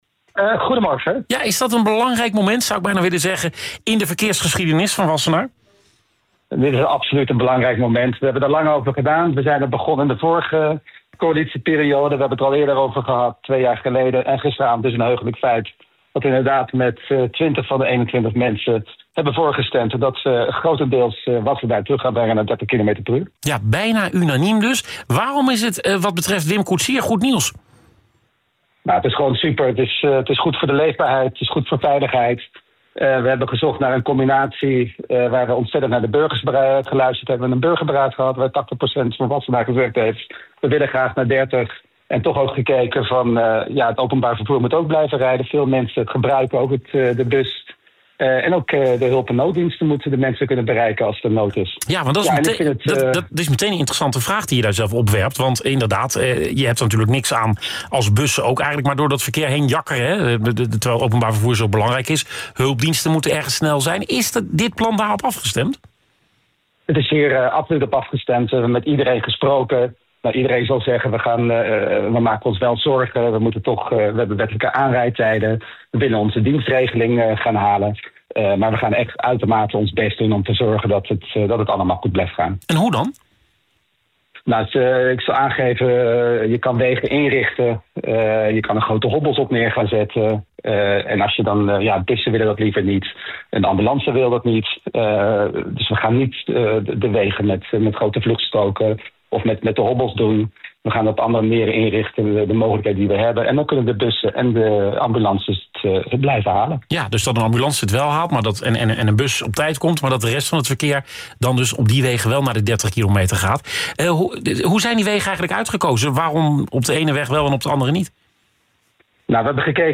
Wethouder Wim Koetsier vertelt in West Wordt Wakker over de invoer van 30km zones in Wassenaar: